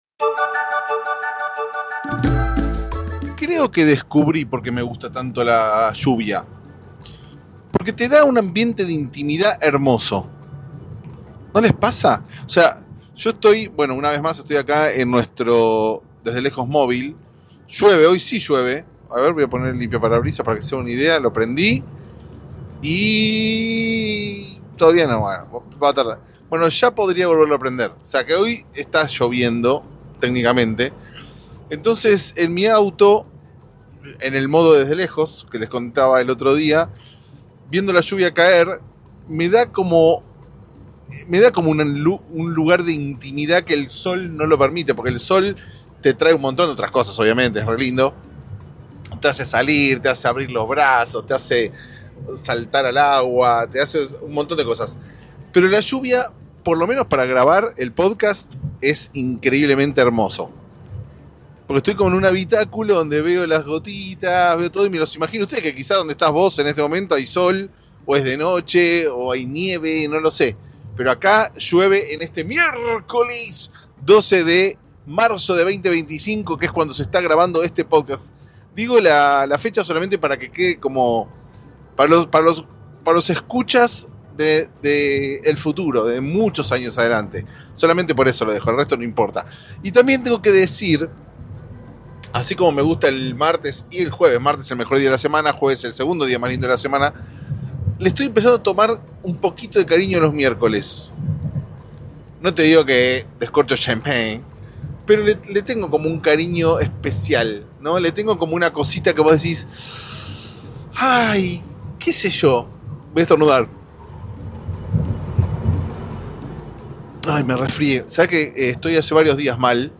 Un poco congestionado, un poco dolorido, pero con la certeza de que este episodio te toca el alma (????)
(por qué habla en plural si está solo, no?)